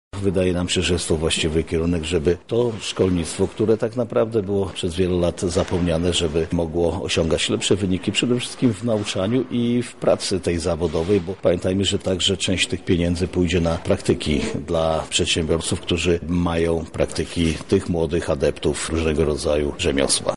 J. Stawiarski – mówi marszałek województwa lubelskiego Jarosław Stawiarski.